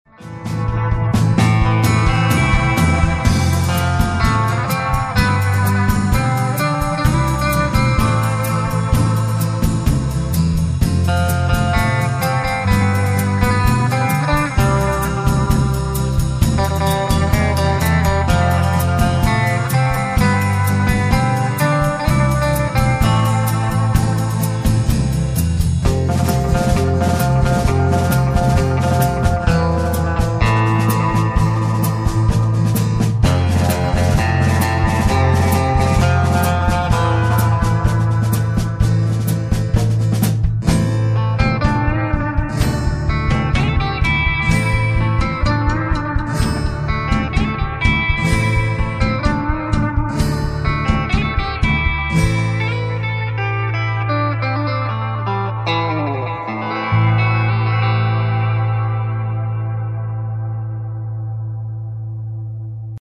guitare solo
guitare basse
Batteur
en tant que guitariste rythmique